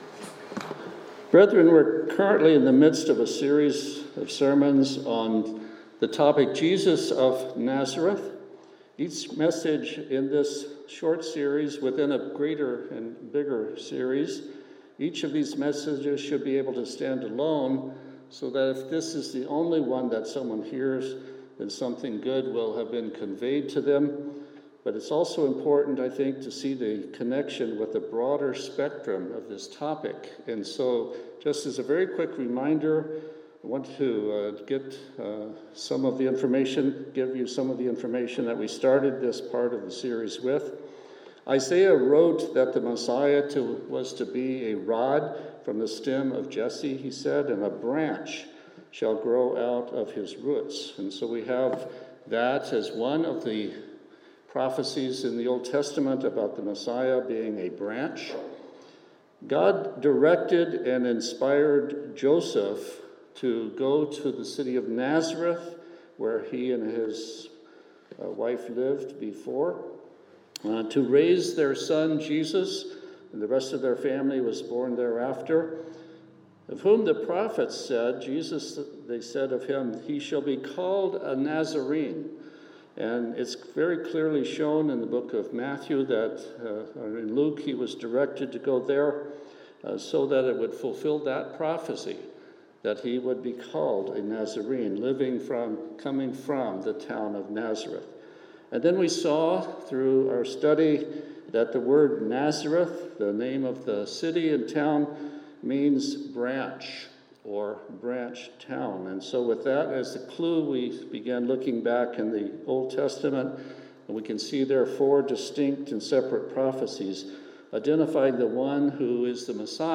In this sermon we consider the prophecy that the Messiah would be a Man.